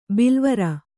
♪ bilvara